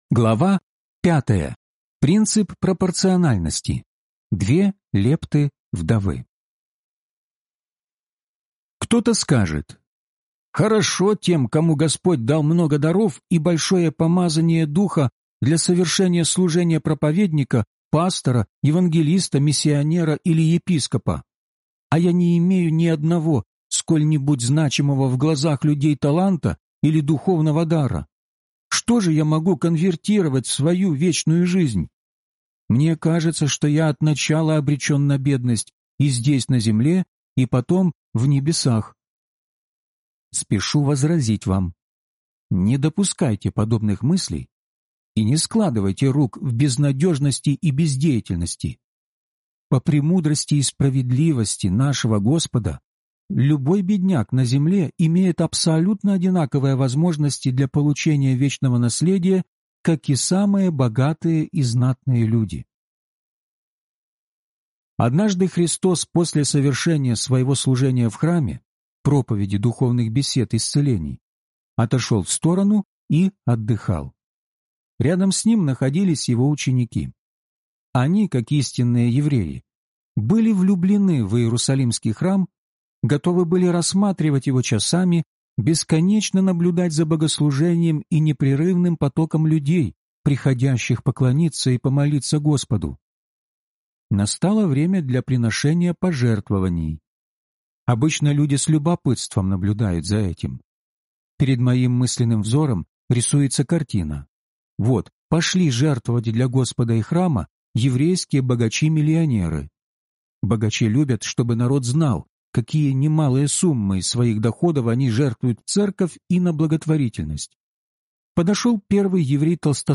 Судилище Христово (аудиокнига) - День 5 из 12